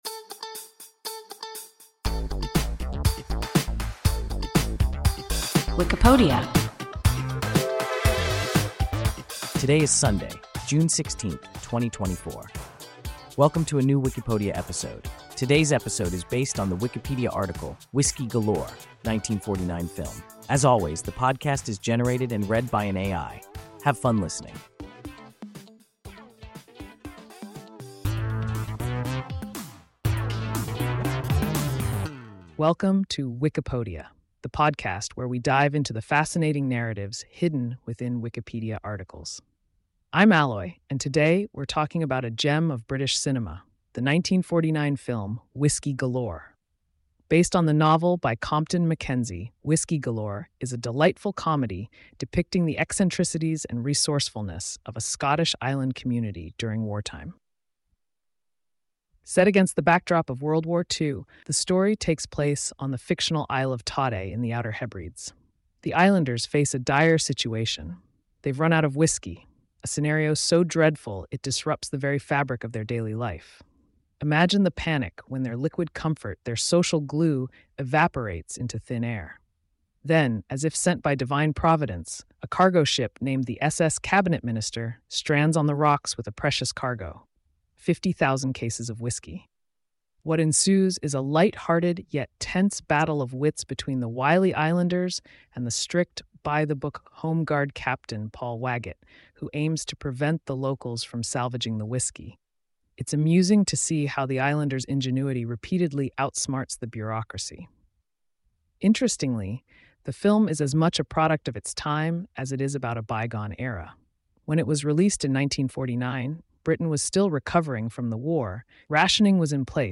(1949 film) – WIKIPODIA – ein KI Podcast